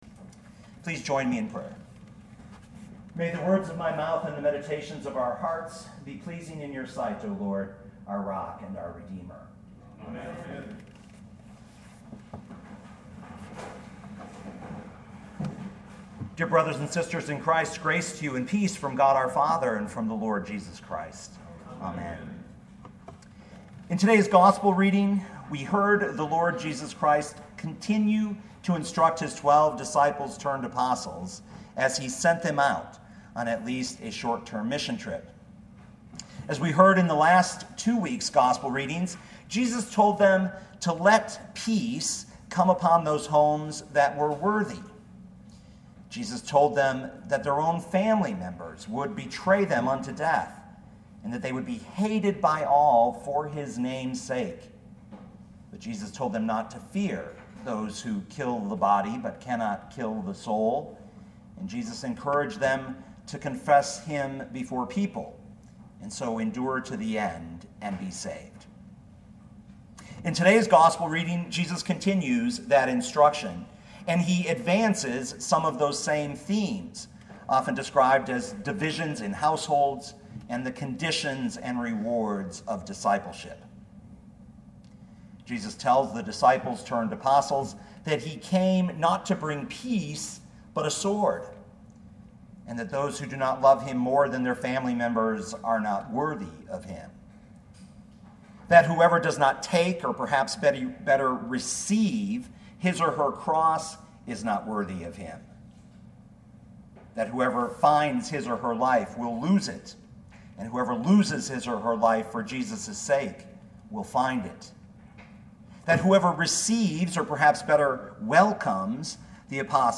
2017 Matthew 10:34-42 Listen to the sermon with the player below, or, download the audio.